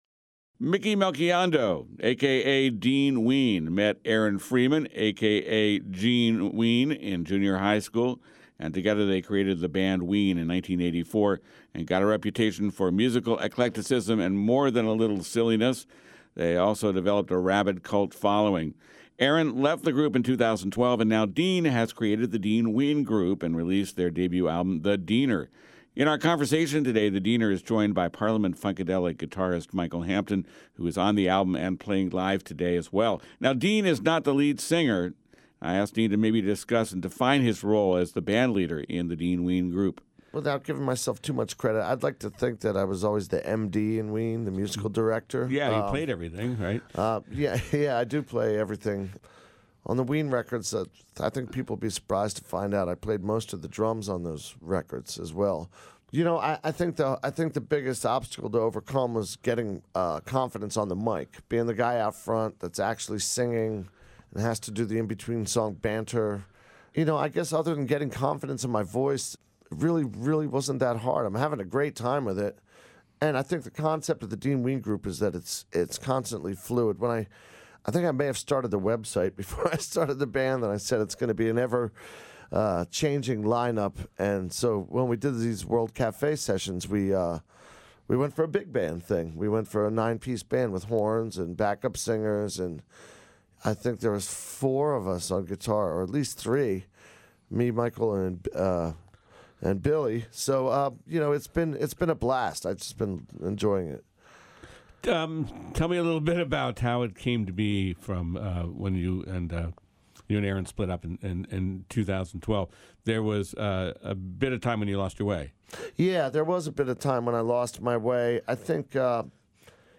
Hear a live session